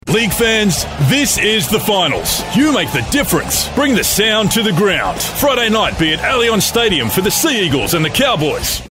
Inglês (australiano)
BarítonoGravesContraltoProfundoBaixo